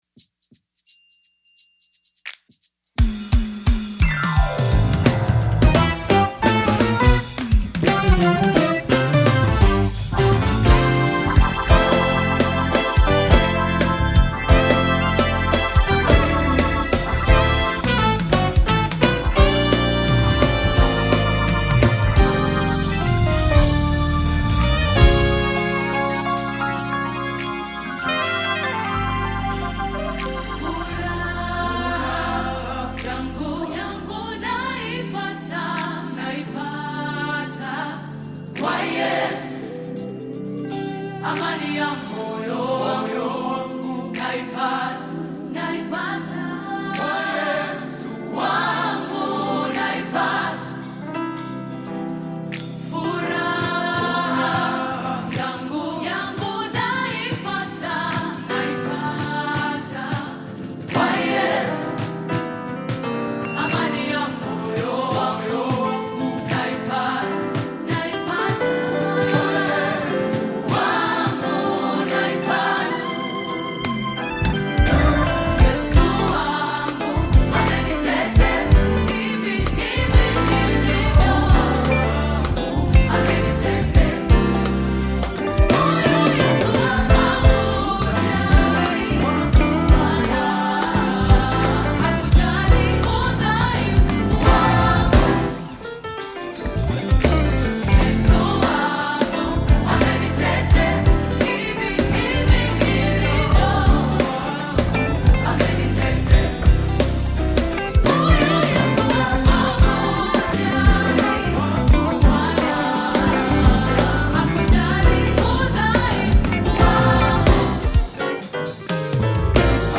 Tanzanian Gospel group of artists, singers, and songwriters
Gospel song